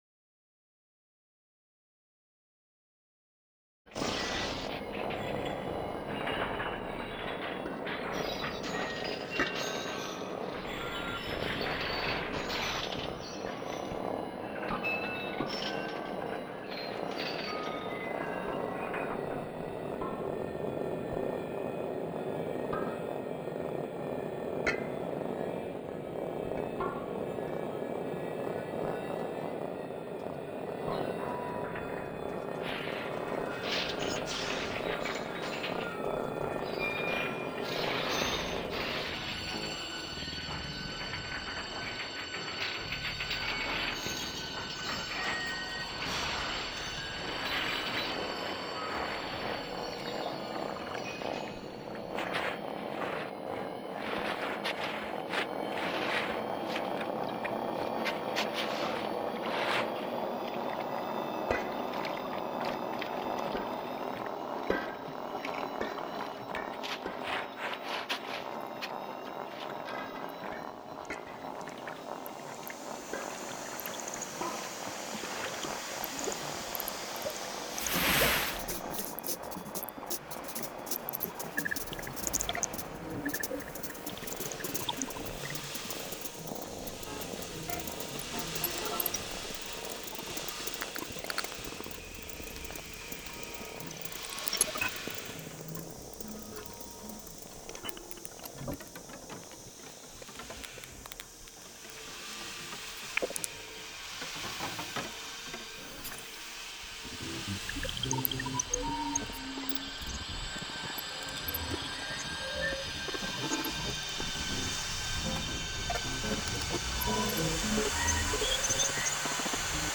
interactive sound installation
listen to sound examples, composed with the sound material used in the berlin version of the »grenzenlose freiheit«: